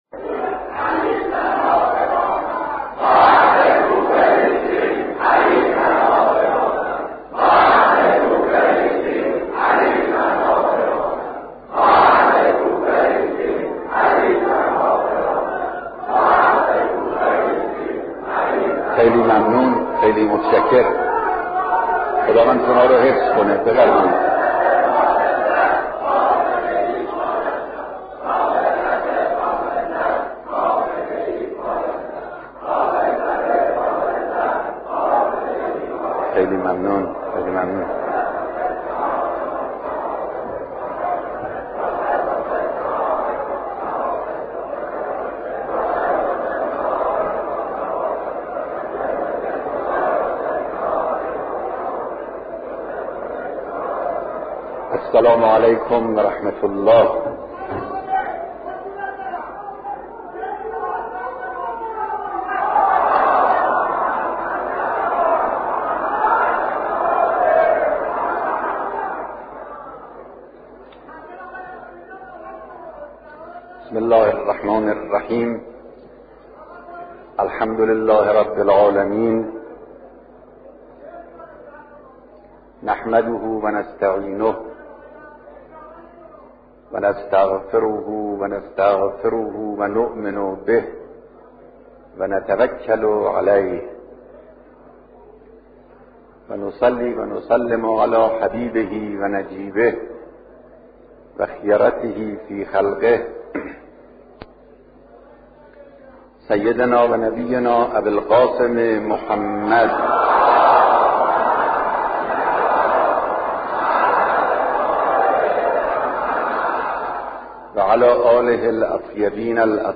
خطبه‌ های نماز جمعه‌ ی تهران
سخنرانی